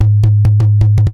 PERC 02.AI.wav